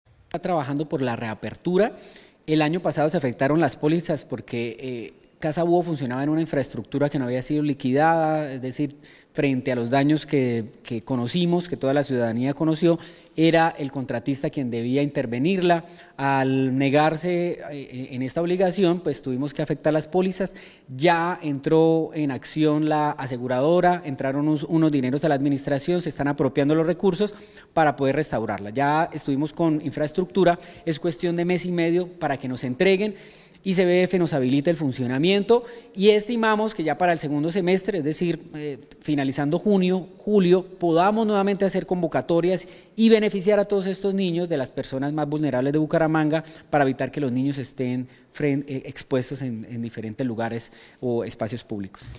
Iván Torres, Secretario de Desarrollo Social de Bucaramanga